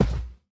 sounds / mob / panda / step1.ogg
step1.ogg